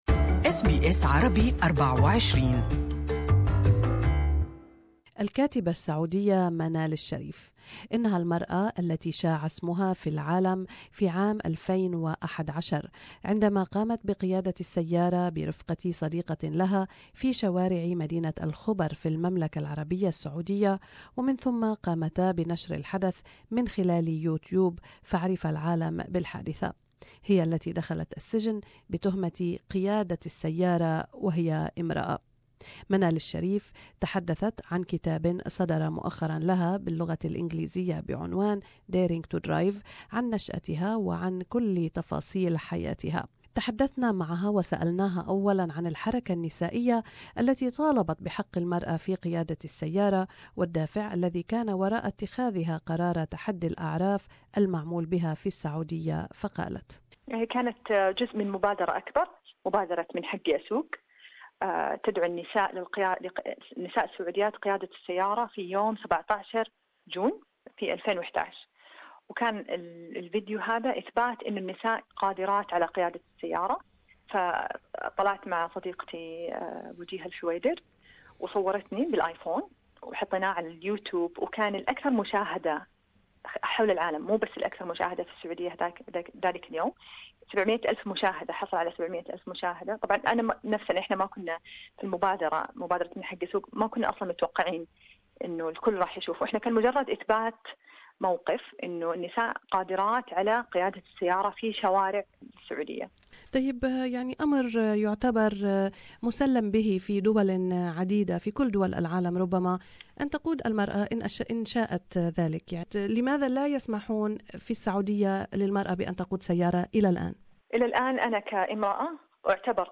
في هذا اللقاء الإذاعي